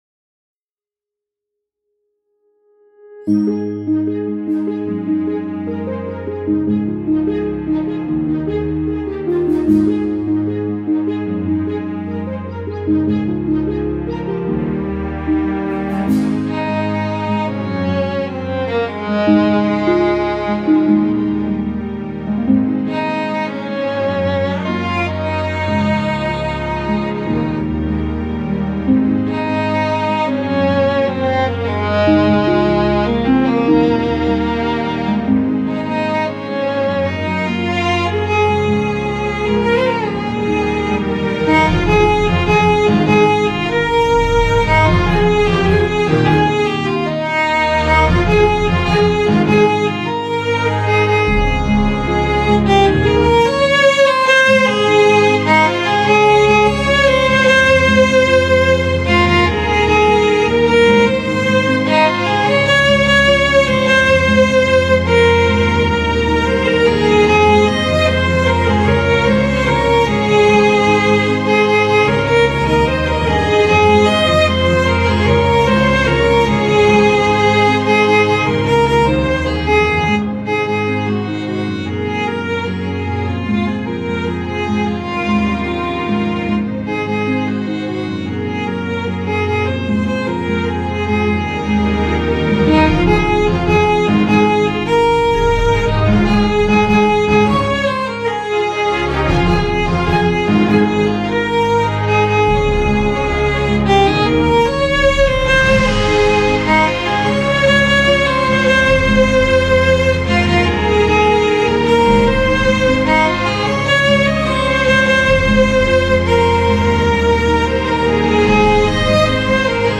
solo violin
high strings playing the melody